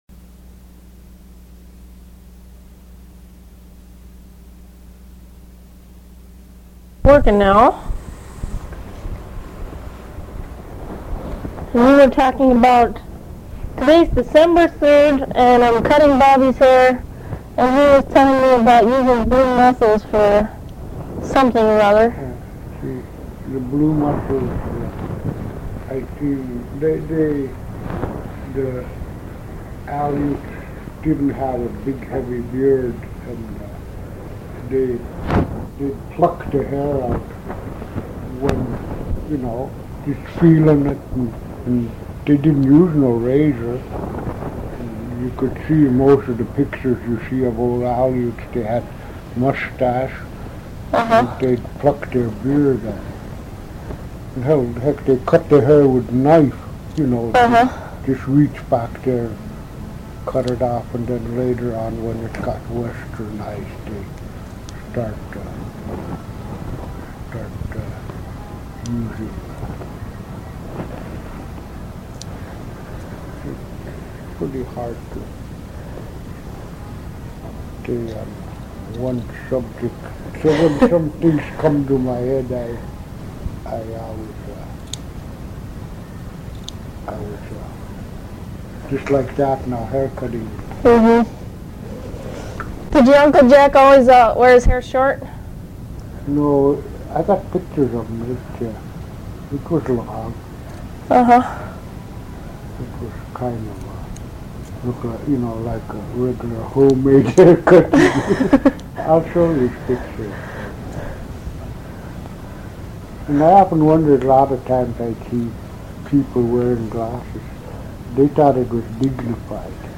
interviews
while giving him a haircut. He talks about going to school in Cordova, uses for seal oil, and the Alutiiq song "Puqisqat" (The Wise Men). (In English) Location: Location Description: Kodiak, Alaska Related Items: